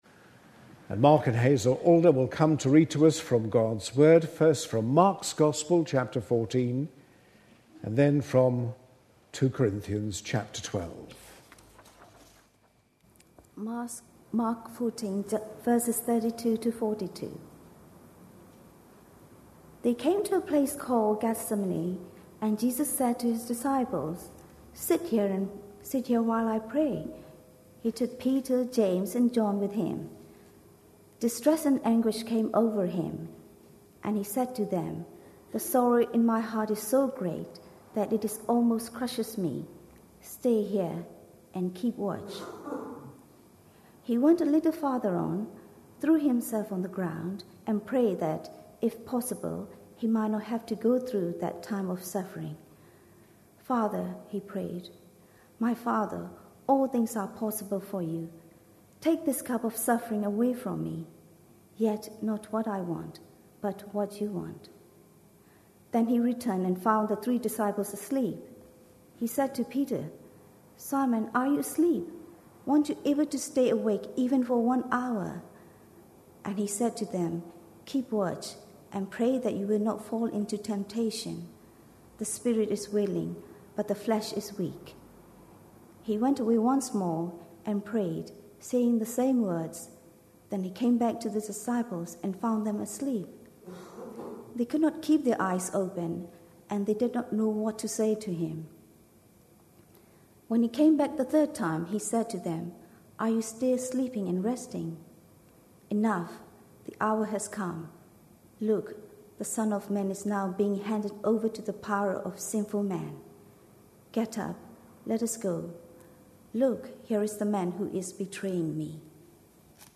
A sermon preached on 31st July, 2011, as part of our God At Work In Our Lives. series.